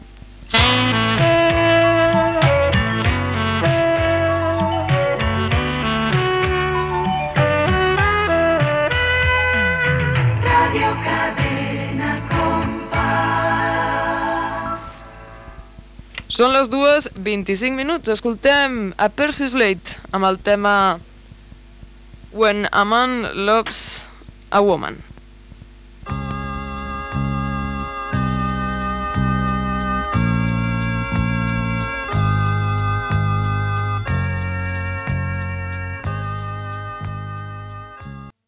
Indicatiu, hora i tema musical.
Musical
FM